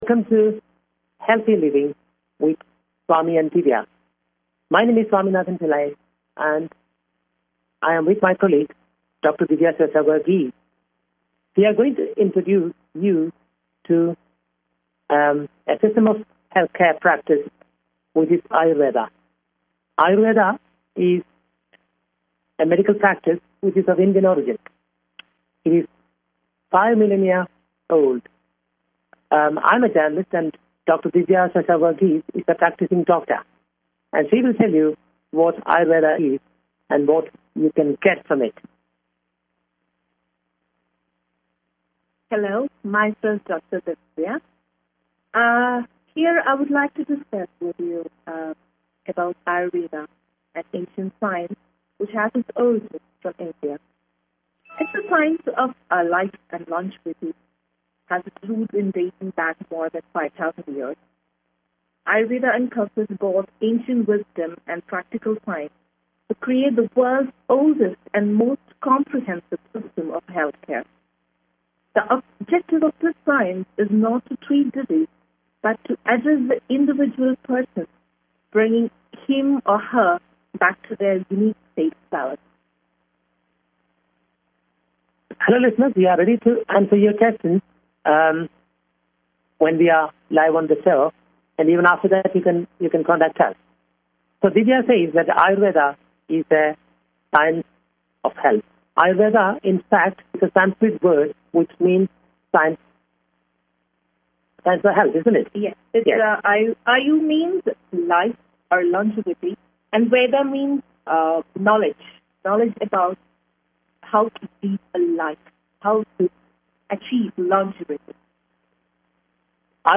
Talk Show Episode, Audio Podcast, Healthy_Living and Courtesy of BBS Radio on , show guests , about , categorized as